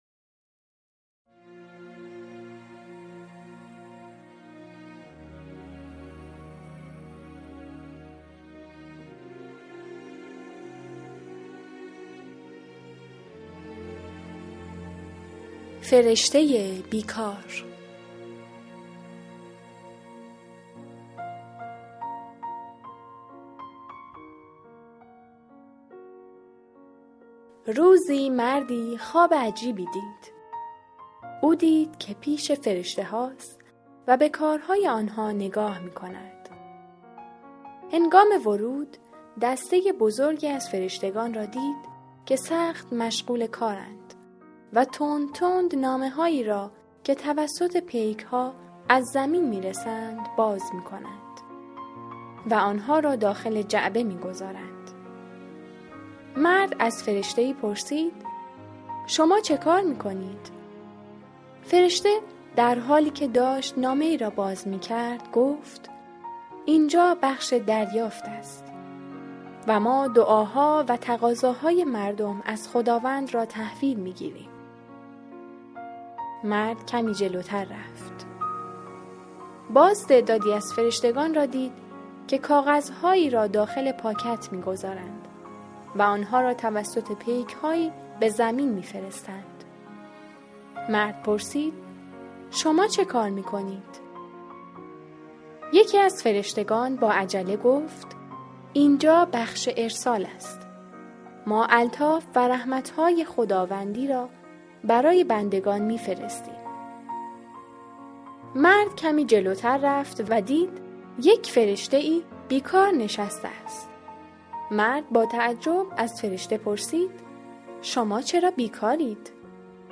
داستان صوتی فرشته بیکار - تیزلند